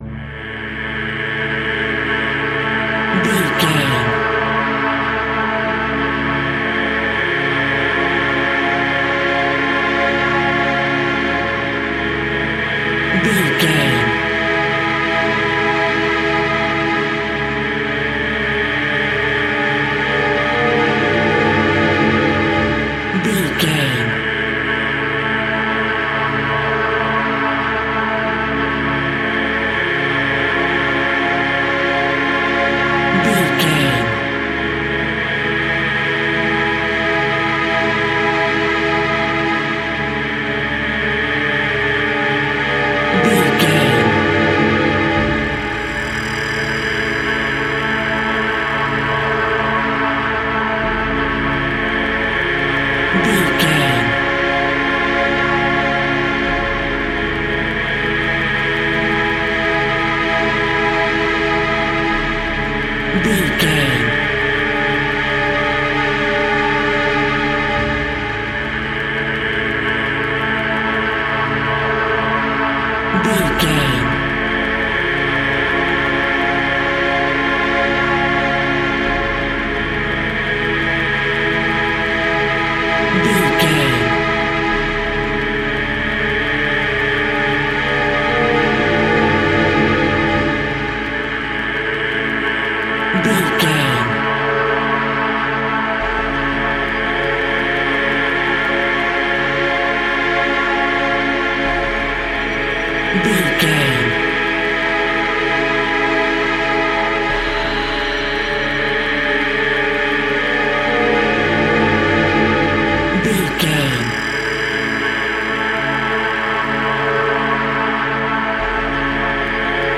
Horror Film Ambience Music Sounds.
Atonal
Slow
ominous
eerie
synthesiser
strings
horror music